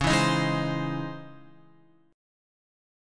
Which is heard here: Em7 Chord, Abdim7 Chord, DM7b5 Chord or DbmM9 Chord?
DbmM9 Chord